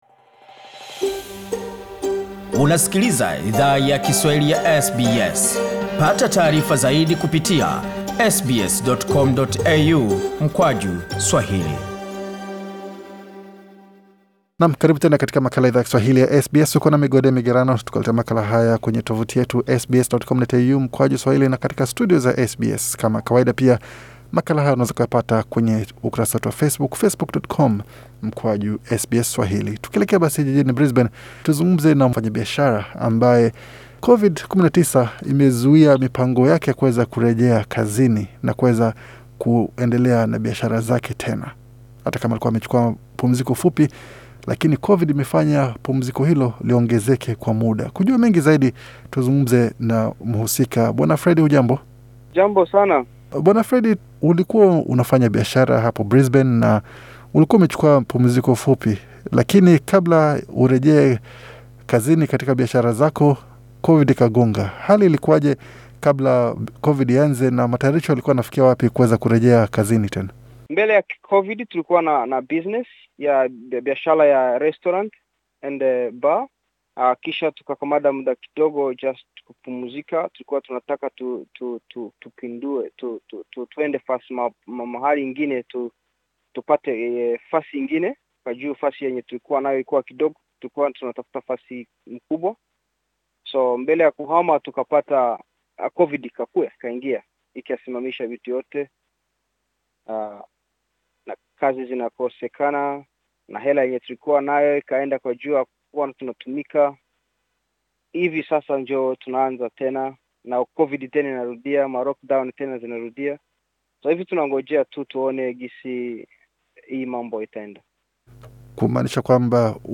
mazungumzo maalum na Idhaa ya Kiswahili ya SBS